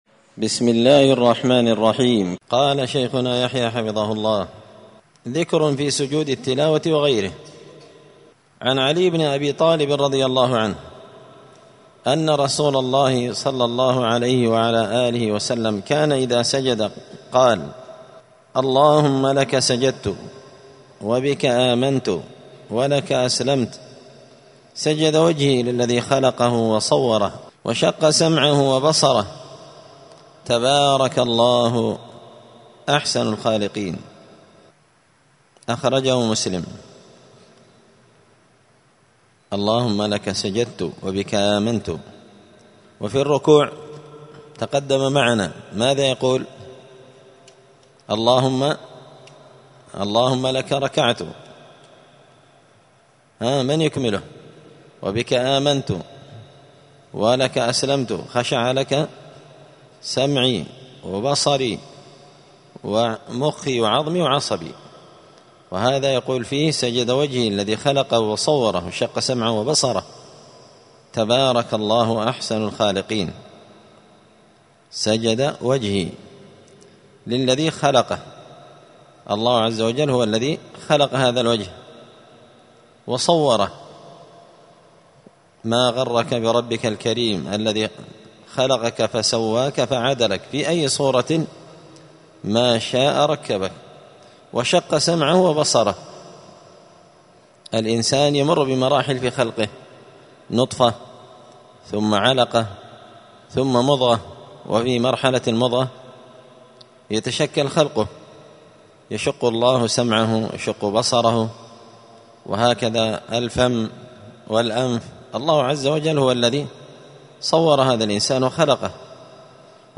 *{الدرس الثاني والثلاثون (32) أذكار الصلاة أذكار السجود ذكر في سجود التلاوة وغيره}*